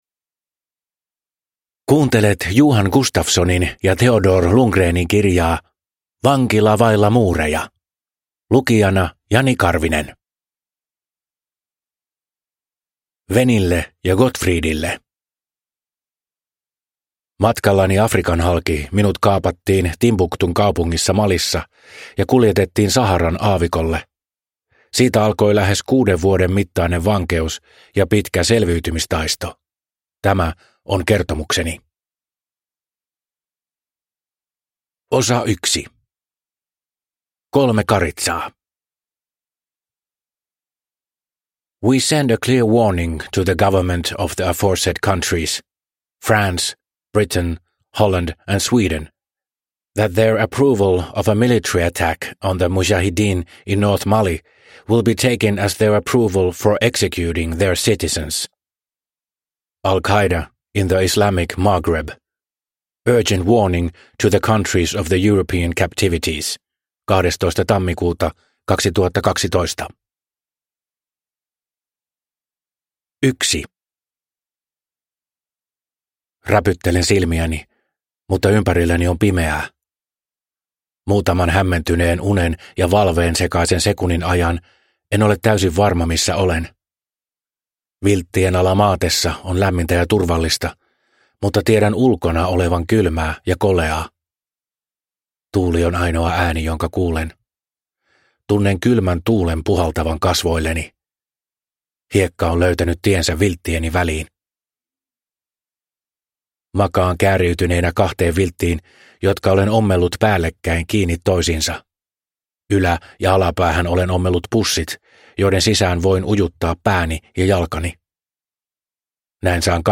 Vankila vailla muureja – Ljudbok – Laddas ner